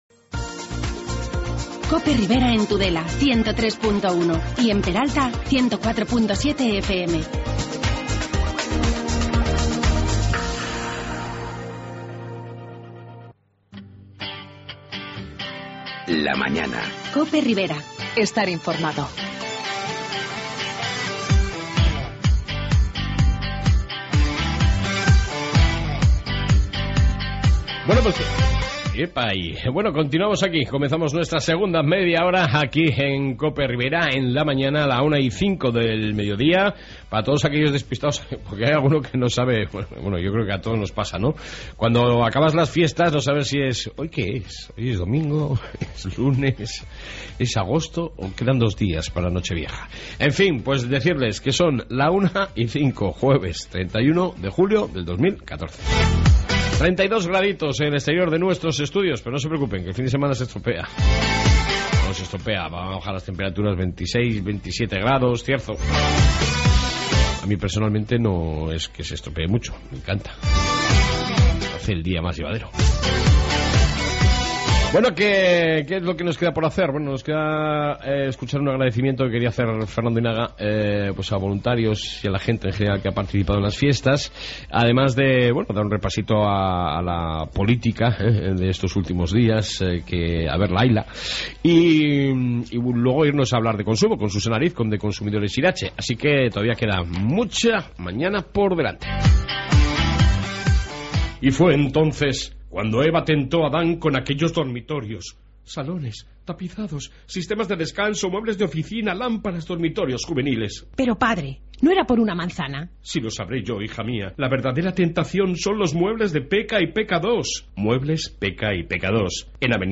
AUDIO: en esta 2 parte info de la ribera y entrevista sobre consumo